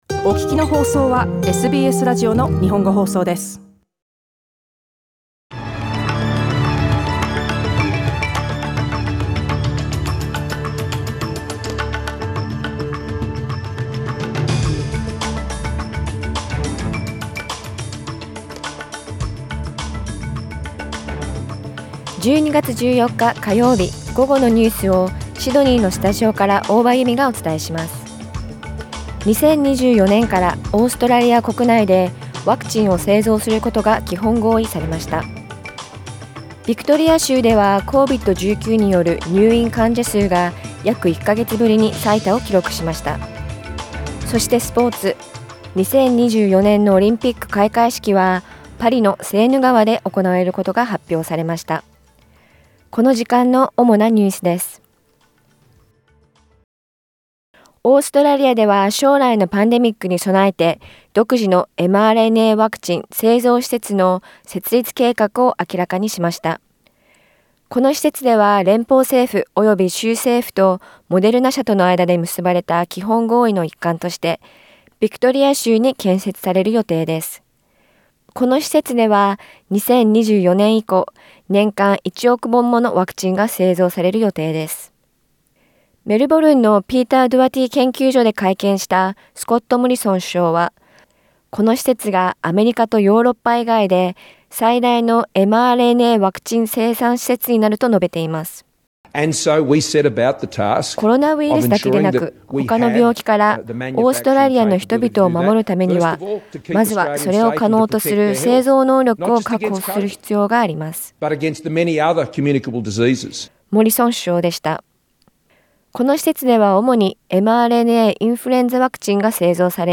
12月14日午後のニュース
12月14日火曜日午後のニュースです。Afternoon news in Japanese, 14 December 2021